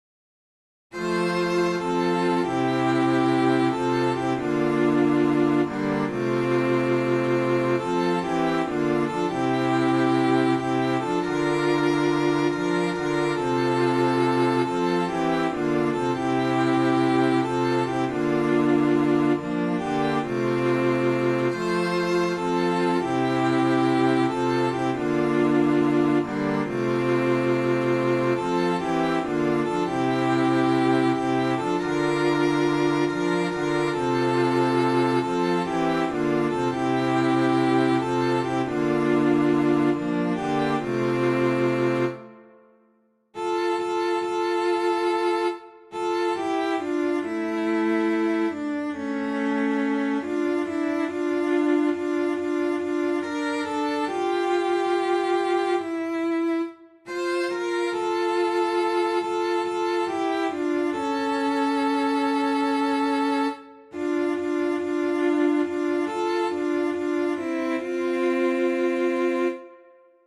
Chants de Méditation